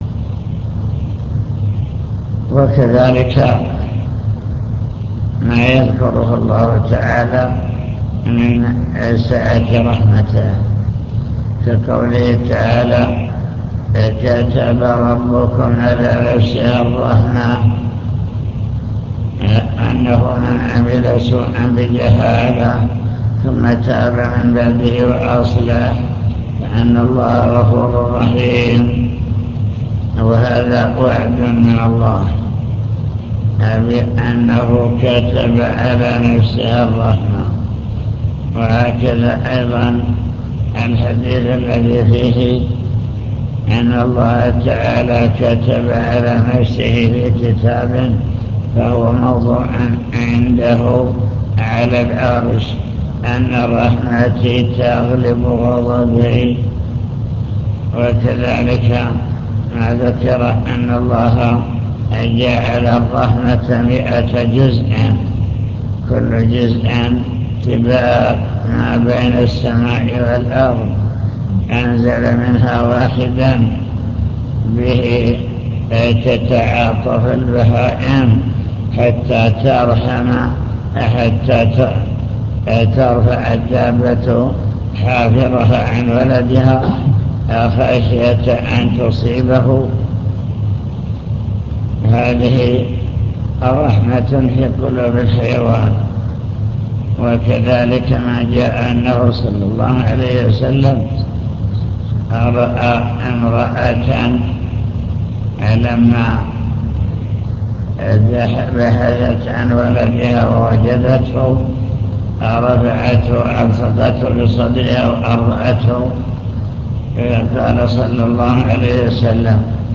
المكتبة الصوتية  تسجيلات - محاضرات ودروس  كتاب التوحيد للإمام محمد بن عبد الوهاب باب قول الله تعالى 'أفأمنوا مكر الله فلا يأمن مكر الله إلا القوم الخاسرون'